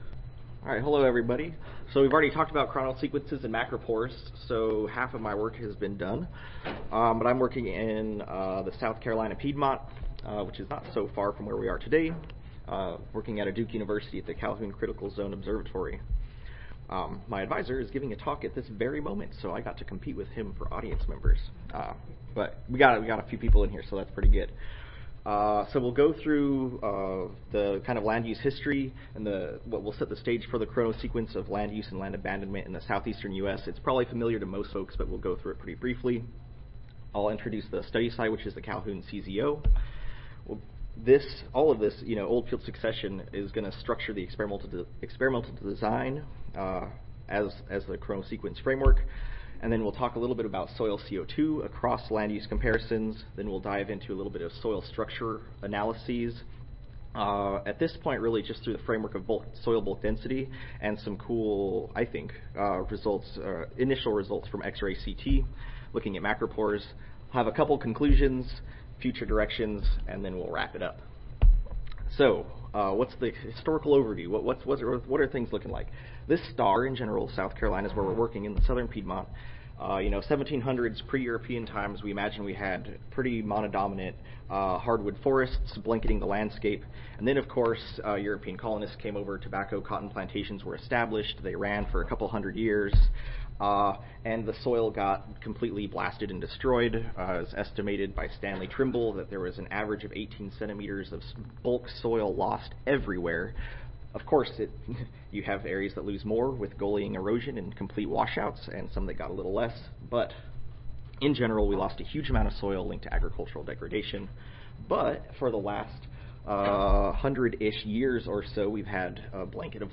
See more from this Division: SSSA Division: Forest, Range and Wildland Soils See more from this Session: Foundations of Ecological Restoration: Recovery of Soil Functions after Drastic Disturbance Oral